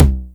4. tr-909